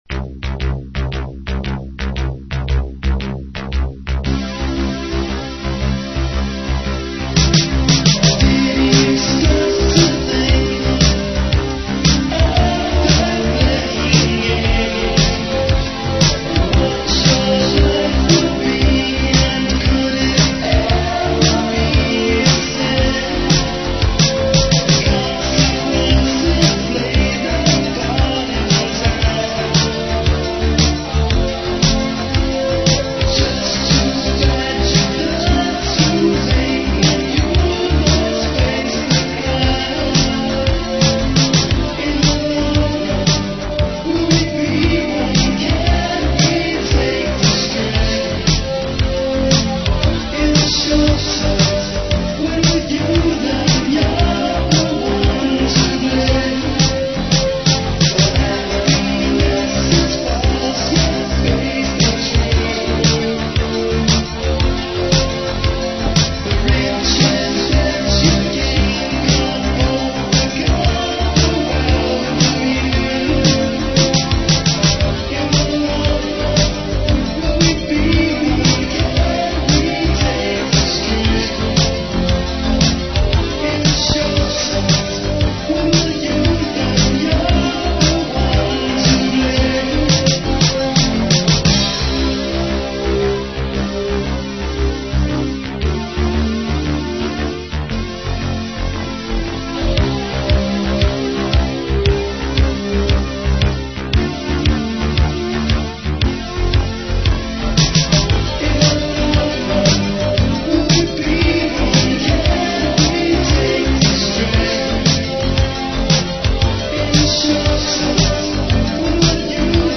By November 1985, I had finished recording all the background instruments on cassette 4-track. I was then using a Roland TR-707 drum-machine with a Yamaha CX-5 computer synth.
We didn't have sound-proofing and so we were affected by the outside traffic noises.
- Behind The Smile, It Haunts Me, Hand On Heart, Long Run, What I'm Needing and Summer Again are from the final "demo" recorded at my flat in Pembroke Road.